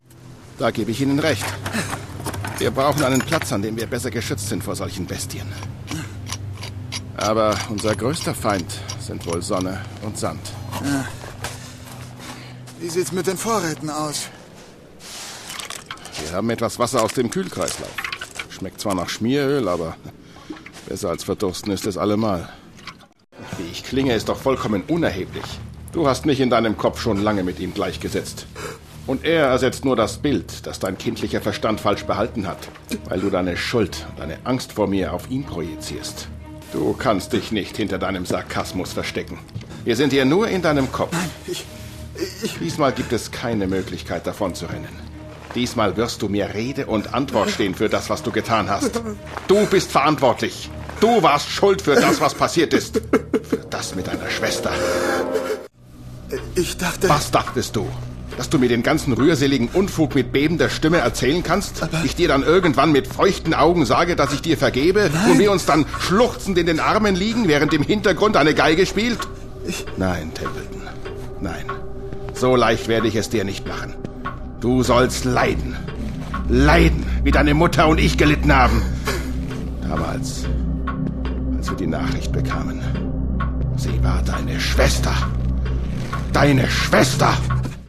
Sprachproben
Männlich
Mezzo-Sopran / Bariton / Mittel
Alt / Bass / Tief
Aber auch Sachtexte z.B. für Imagefilme, E-Learning, Werbung oder Dokumentationen, erwecke ich durch meine warme, sonore, vertrauenerweckende Stimme zum Leben.